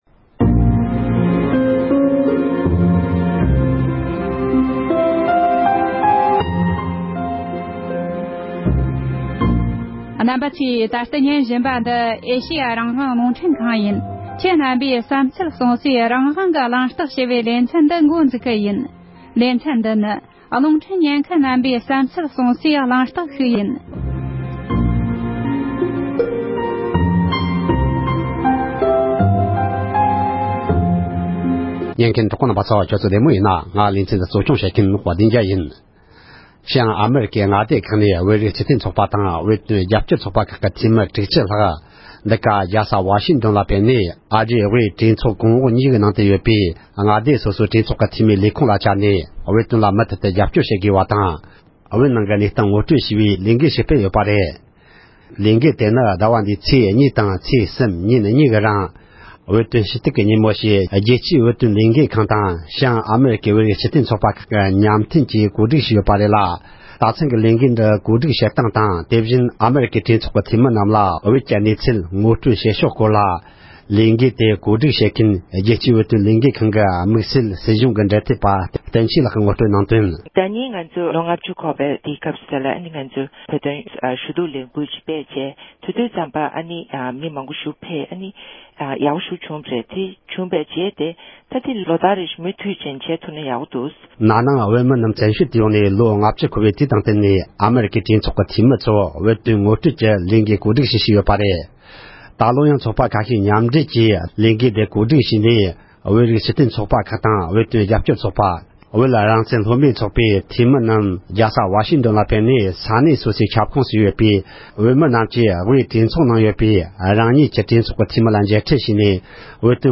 བྱང་ཨ་རིའི་བོད་རིཊ་སྤྱི་མཐུན་ཚོགས་པ་ཁག་གི་འཐུས་མི་རྣམས་ཀྱིས་བོད་དོན་ཞུ་གཏུགས་ཀྱི་ལས་འགུལ་ཞིག་སྤེལ་ཡོད་པའི་སྐོར་གླེང་མོལ།